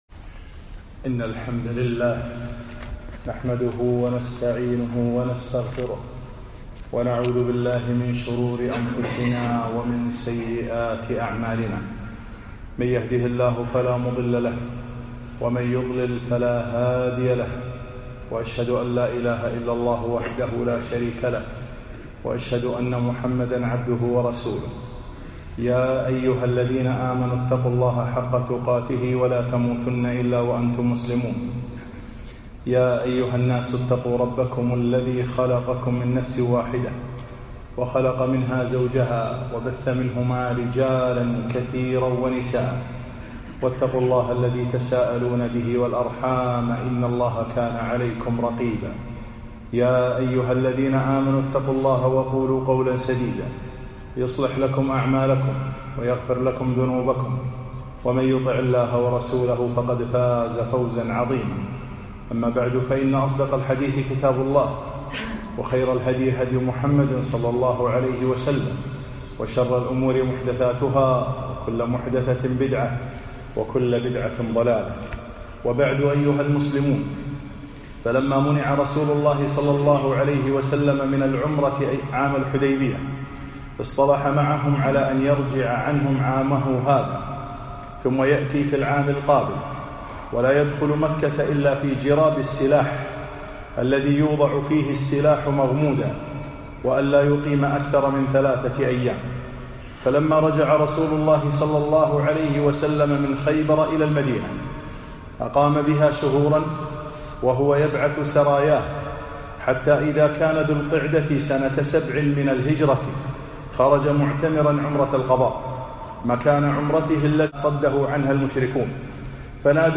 خطب السيرة النبوية 22